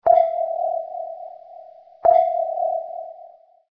Uma sonda sonora especial
Sonar_pings.mp3